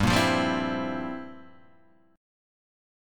Gm13omit5 chord {3 1 3 0 1 0} chord